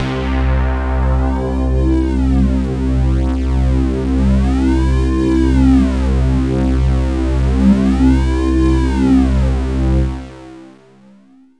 AMBIENT ATMOSPHERES-5 0001.wav